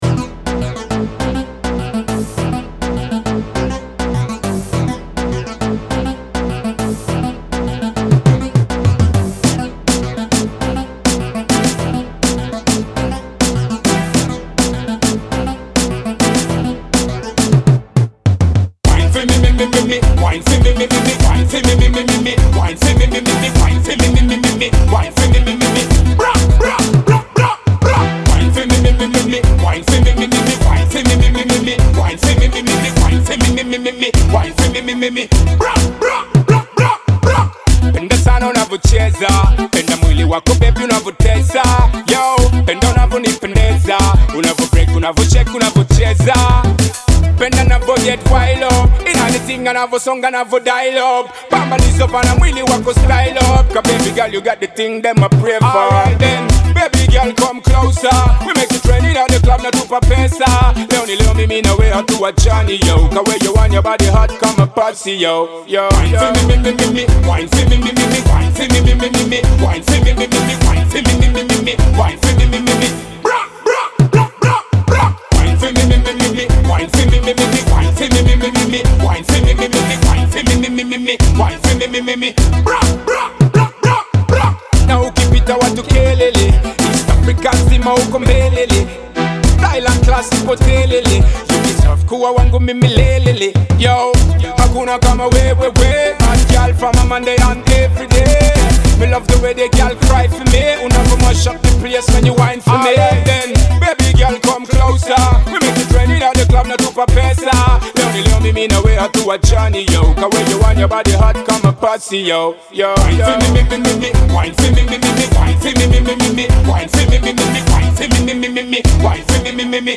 Kenya’s biggest dancehall and ragga artist
club banger that will get you on your feet
dancehall